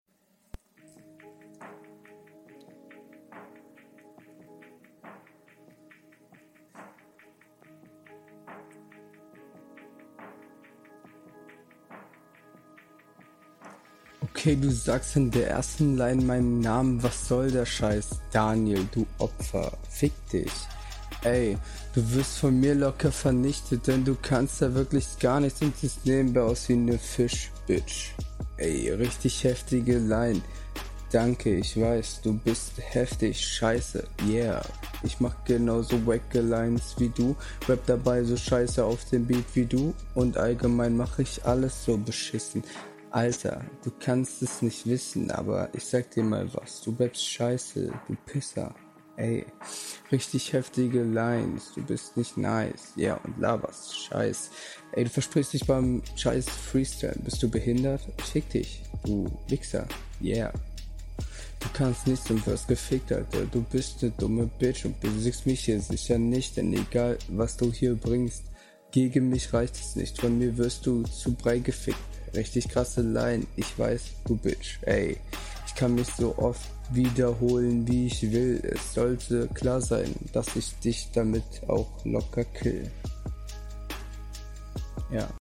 Flow:ja ist eigentlich kein flow vorhanden.
Flow: Flow ist gar nicht vorhanden.